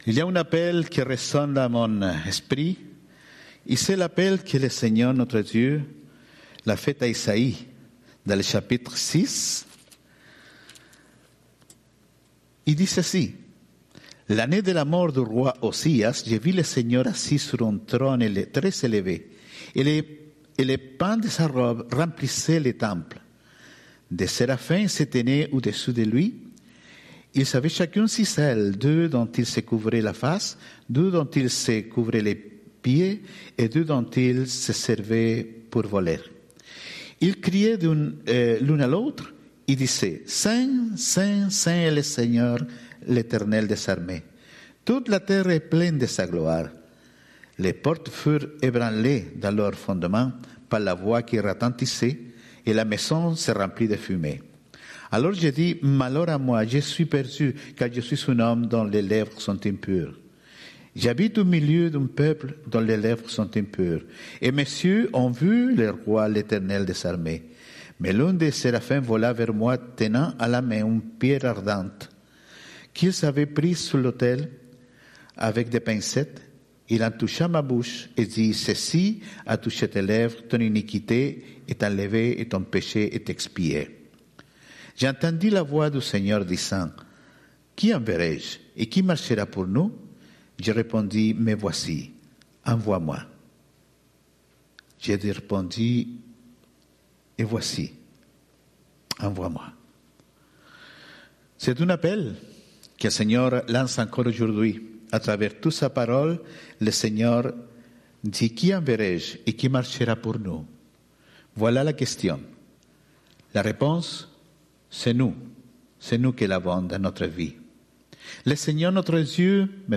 Prédications Date Titre Tribune Audio Vidéo Autre Le 31 janvier 2021 Le vrai repos en Jésus (2e partie) Matthieu 11.28-30 Il y a deux vidéos pour ce message.